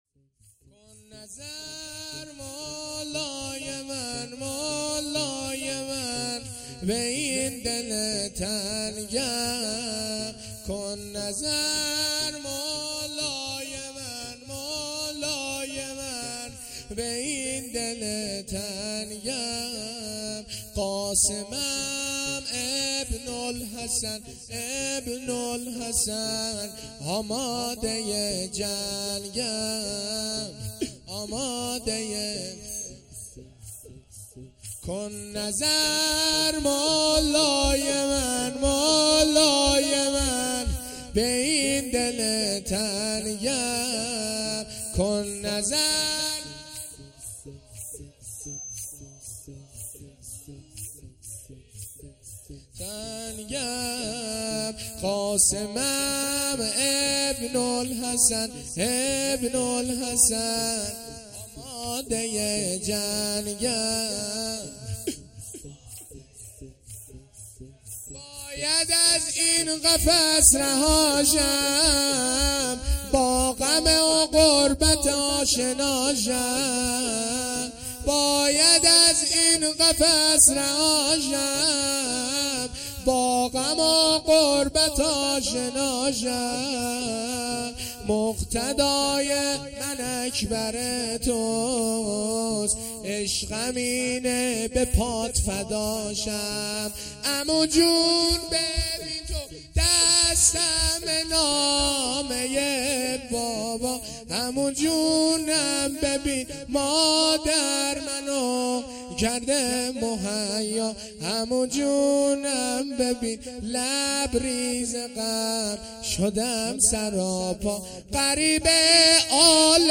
شب ششم محرم الحرام ۱۴۴۳